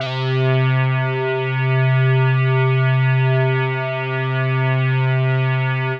C3_trance_lead_2.wav